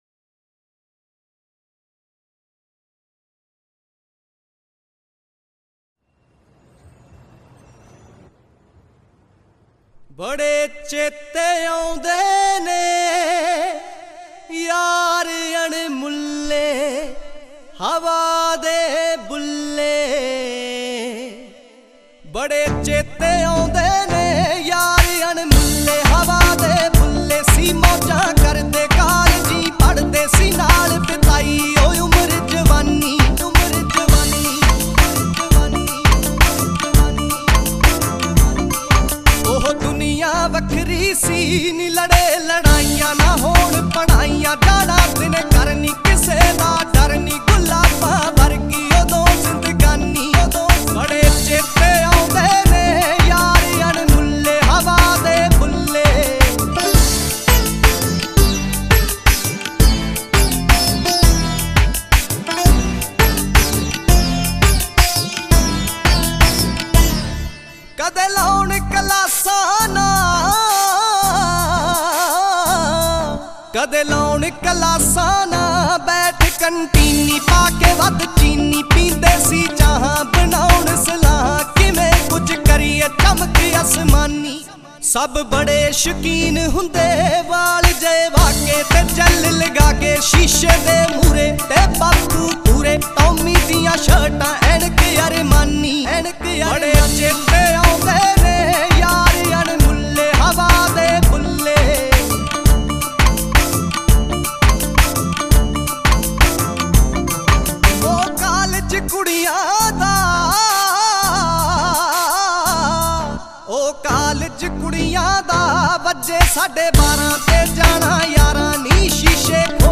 Latest Punjabi Songs